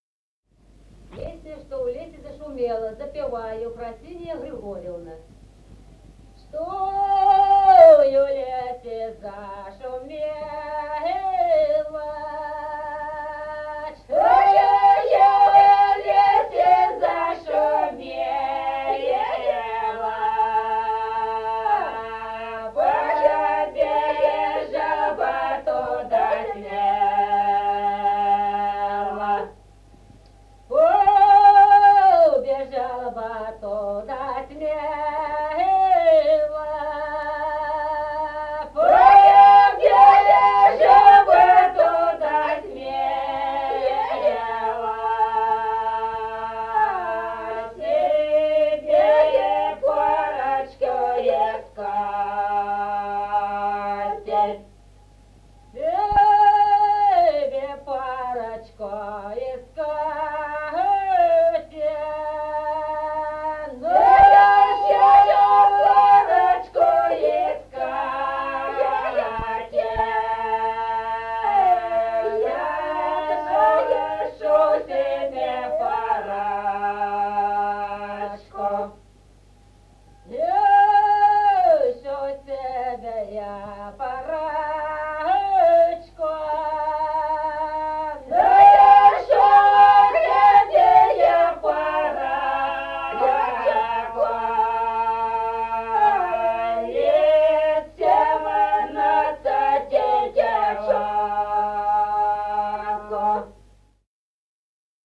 Песни села Остроглядово Чтой в лесе зашумело.
Песни села Остроглядово в записях 1950-х годов